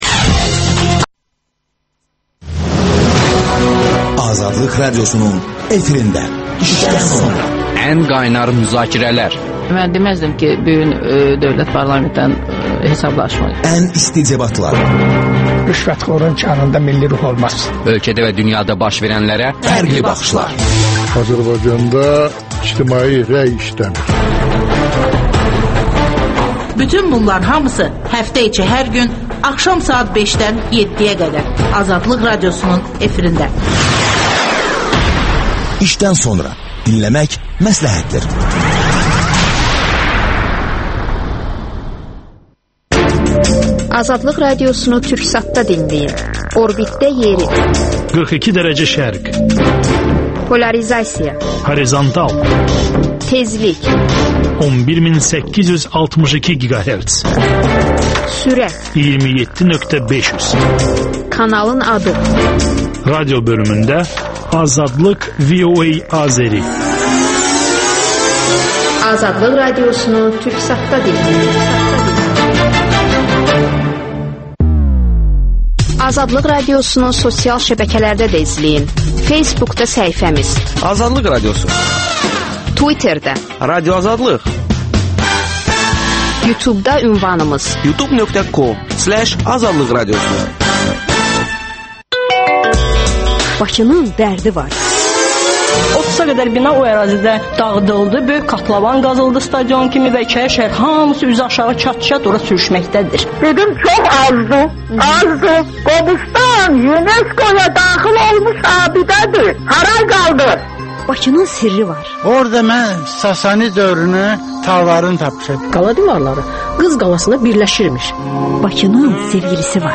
Həbsdən azad olmuş jurnalist canlı efirdə sualları cavablandırır